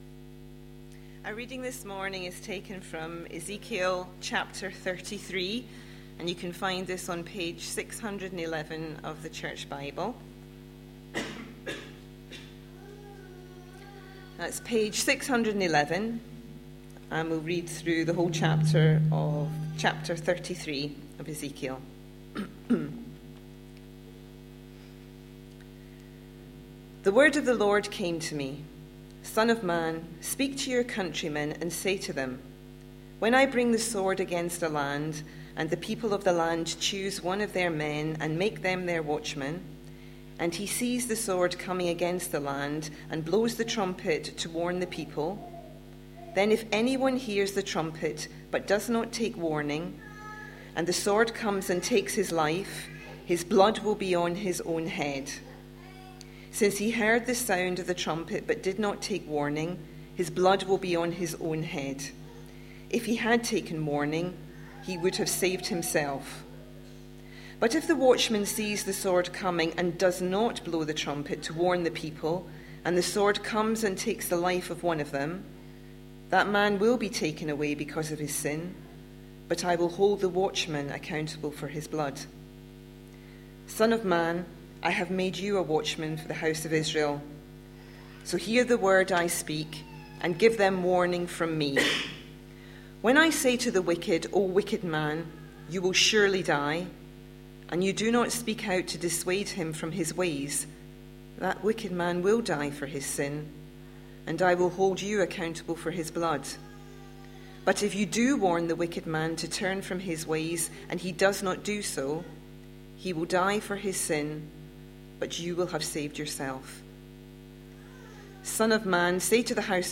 A sermon preached on 3rd August, 2014, as part of our Ezekiel series.